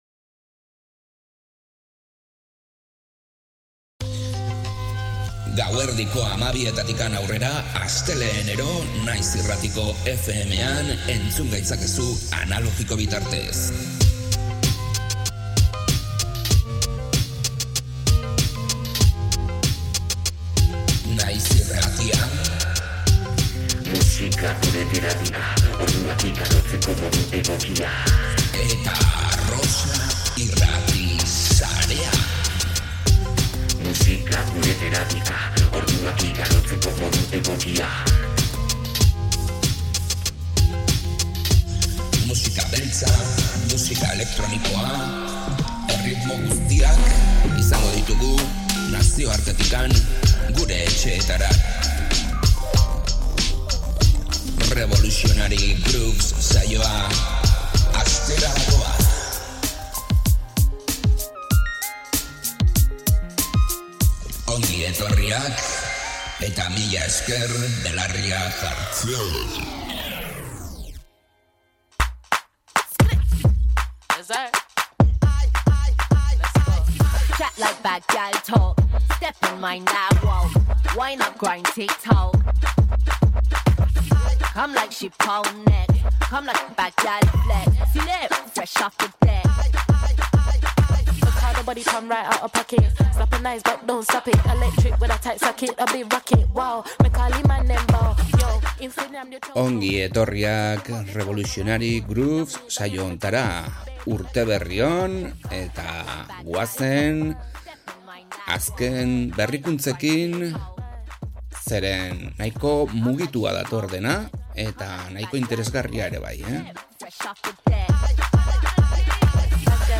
Bailefunk brasildar musika elektronikoa